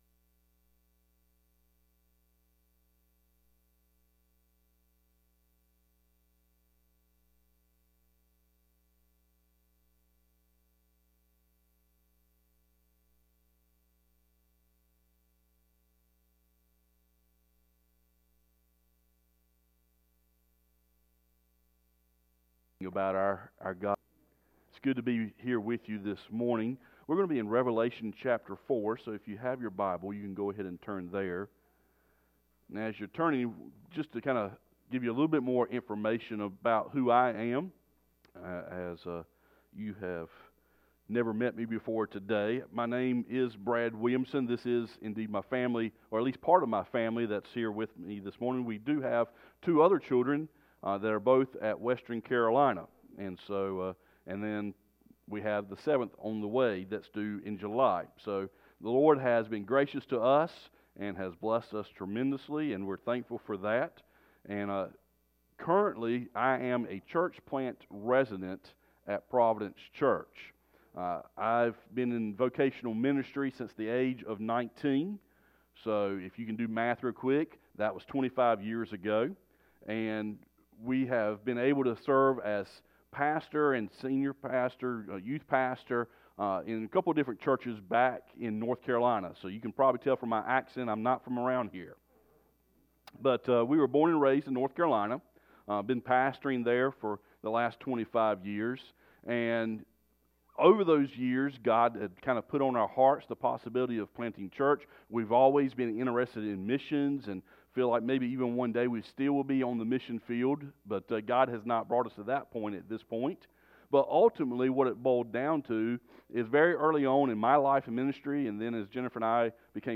Sermons and Lessons - Faith Bible Fellowship